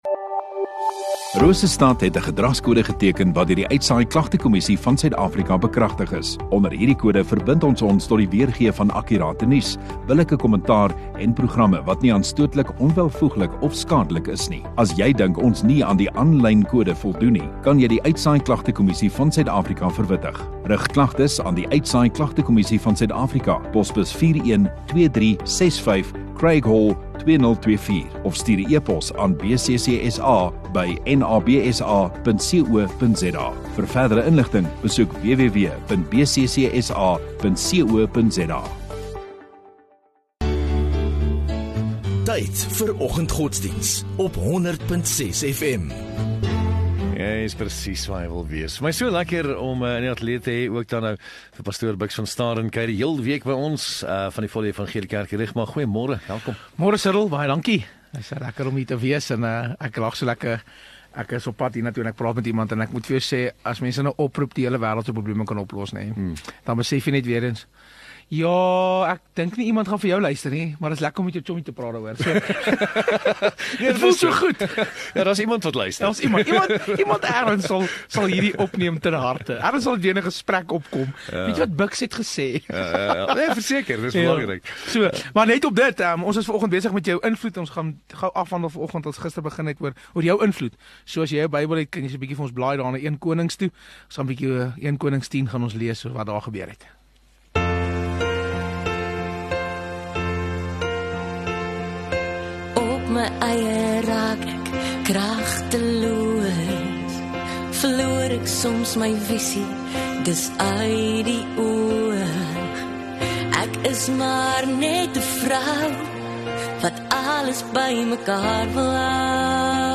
28 May Dinsdag Oggenddiens